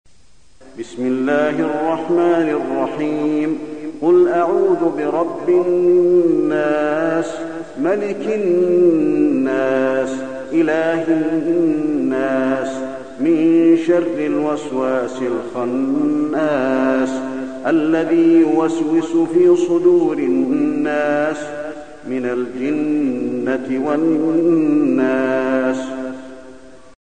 المكان: المسجد النبوي الناس